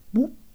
bwoop.wav